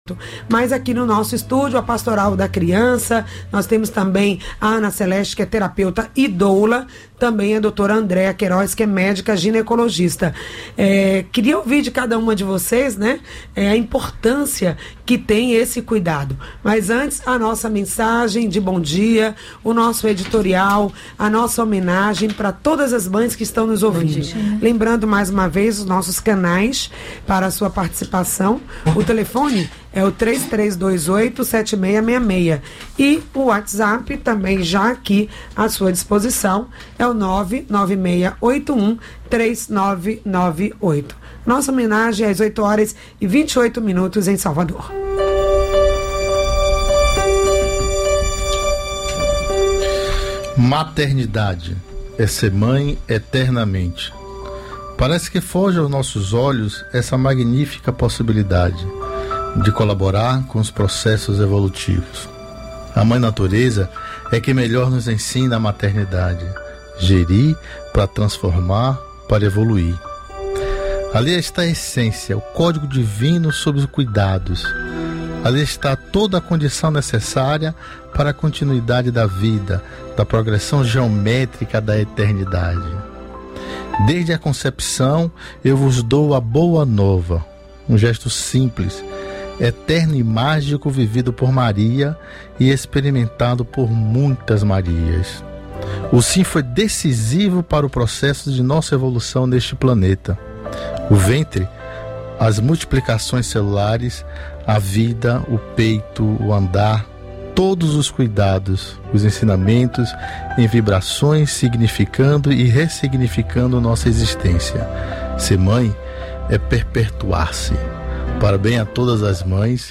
no Programa Saúde no ar com transmissão ao vivo pelas Rádios Excelsior AM 840